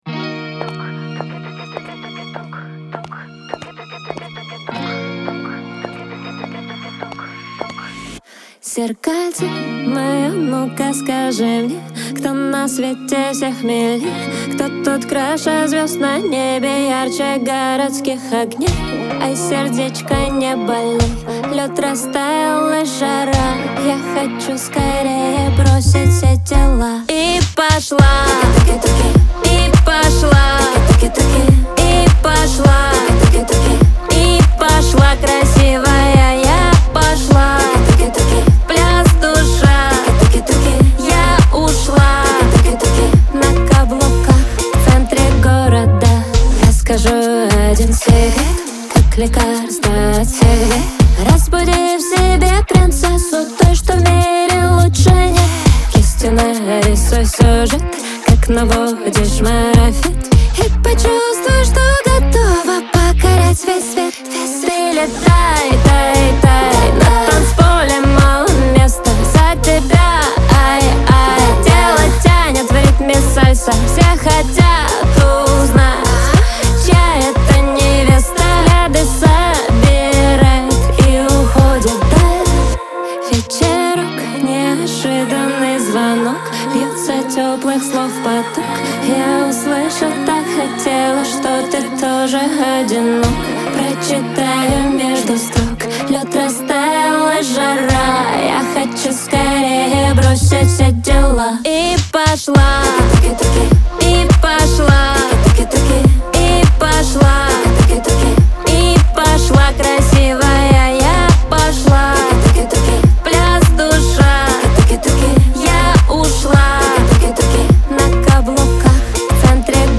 Веселая музыка , Лирика